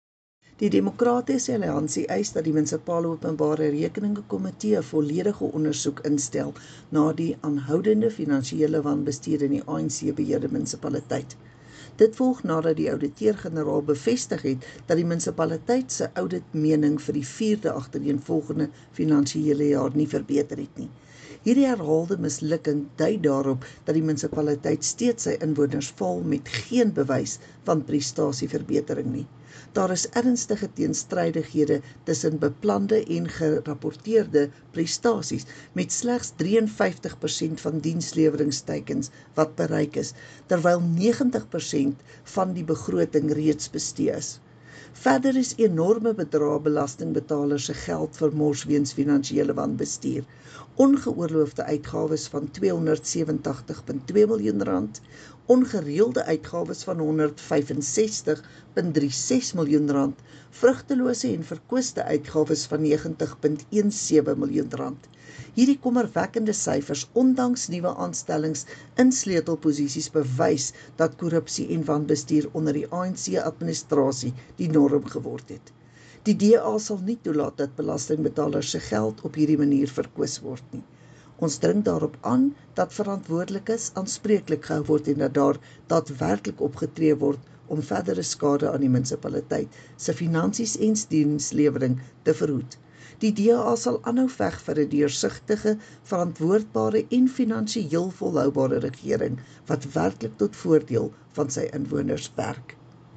Afrikaans soundbite by Cllr Estie Senekal